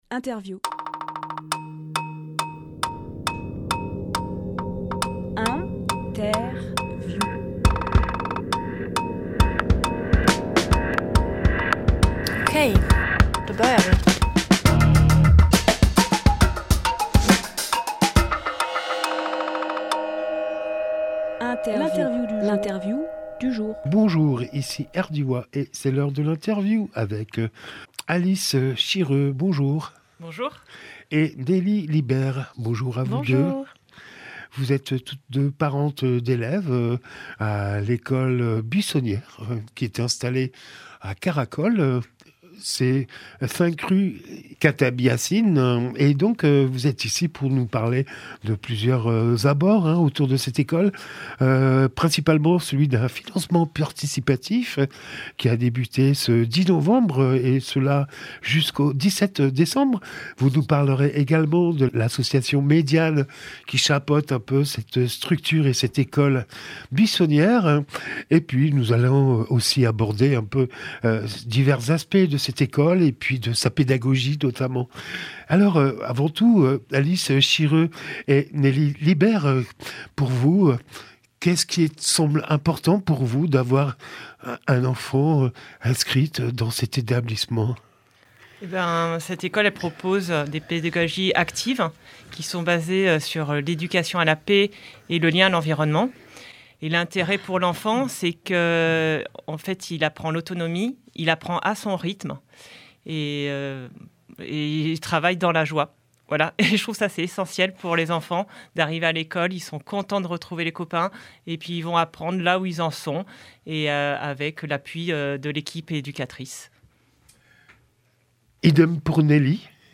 Emission - Interview L’école buissonnière investit Caracole Publié le 30 novembre 2024 Partager sur…
lieu : studio Rdwa